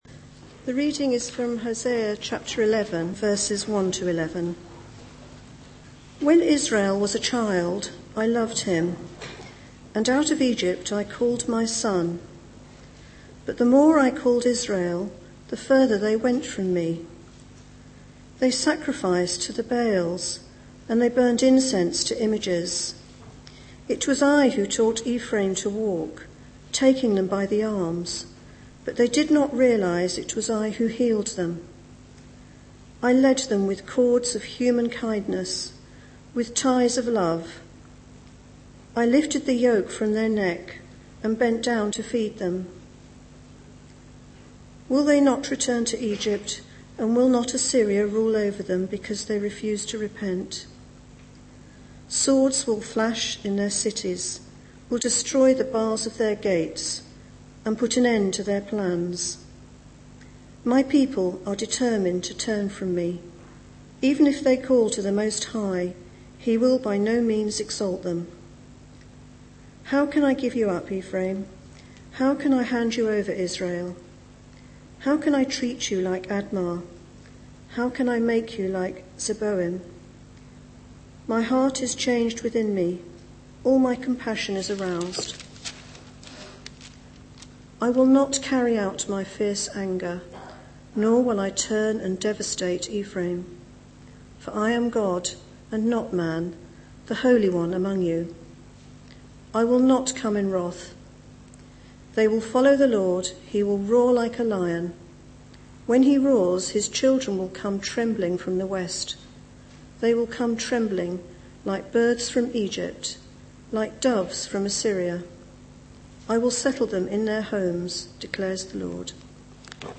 Sunday Mornings